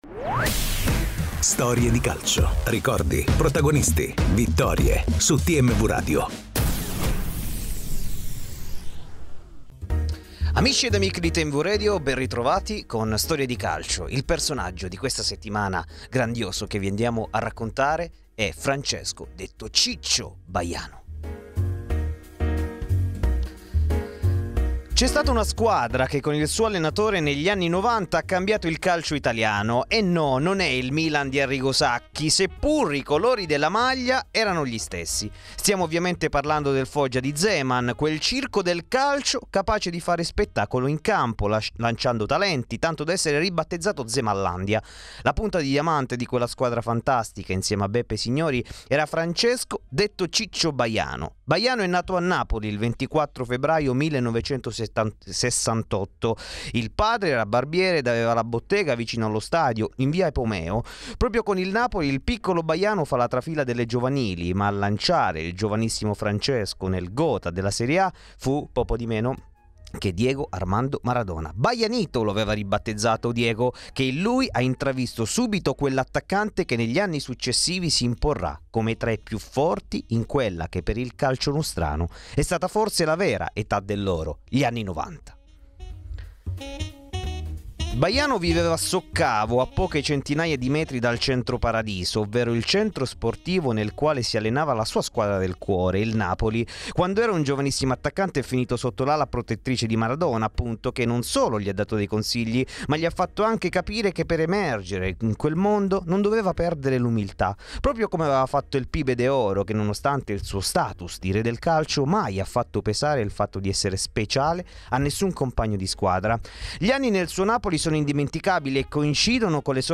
E a TMW Radio ha raccontato la sua carriera a Storie di Calcio.